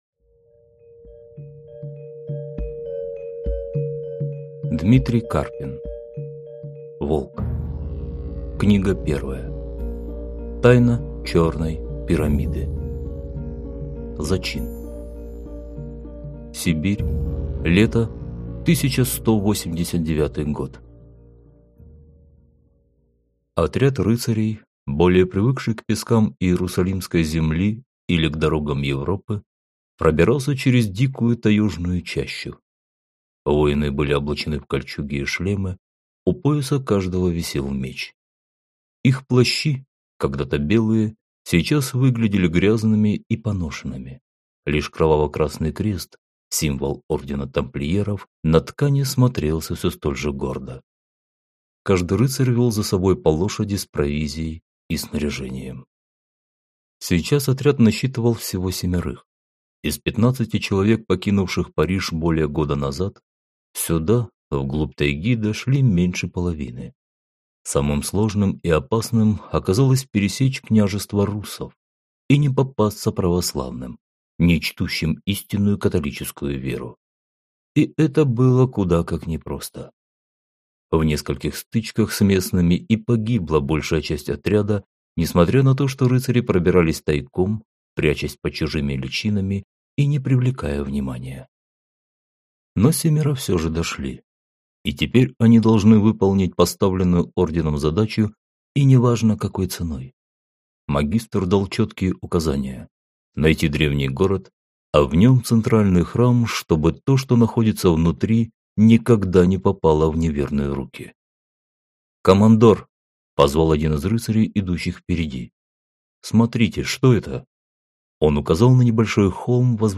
Аудиокнига Тайна Черной пирамиды | Библиотека аудиокниг